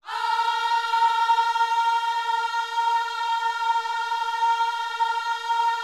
OHS A#4C.wav